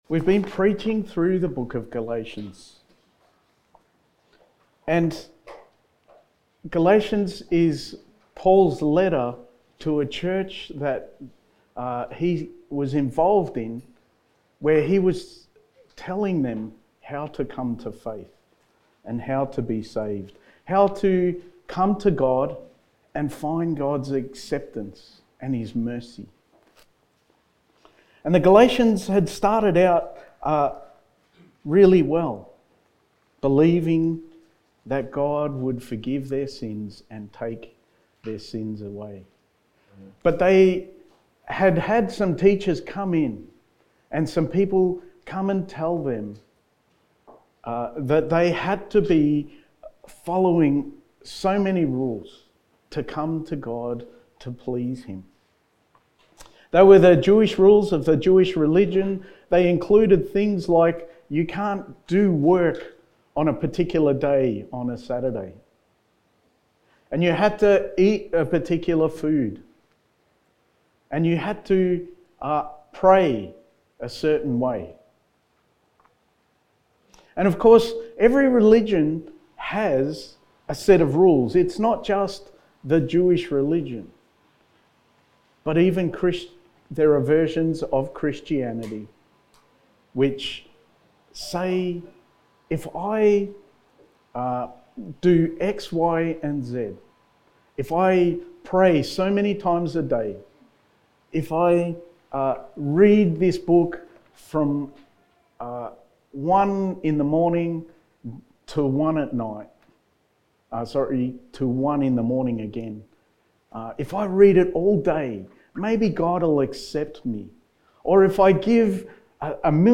Sermon
Galatians Series Passage: Galatians 3:15-18 Service Type: Sunday Morning Sermon 9 « Paul’s Pastoral Prayer High Priest